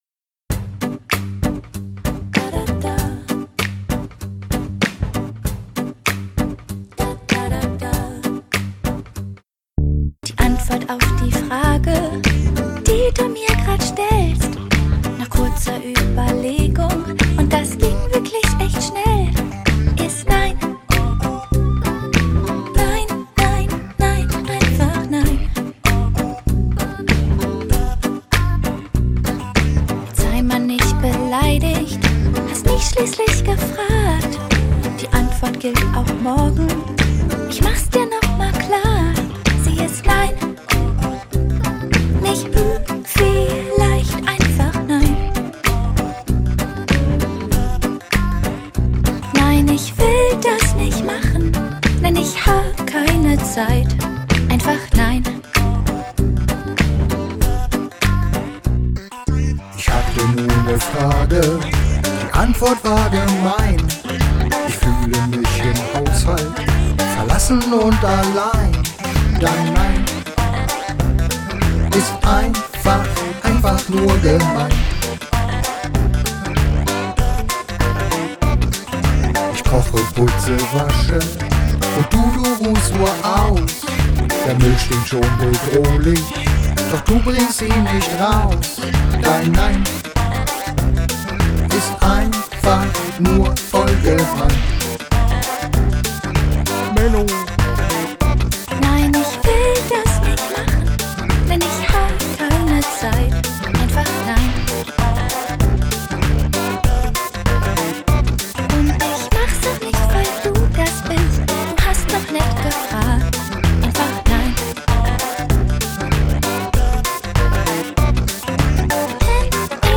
Kopfstimme